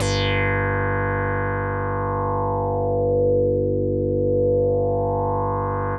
G2_raspy_synth.wav